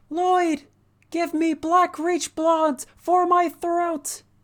DRG-Femboy-Voice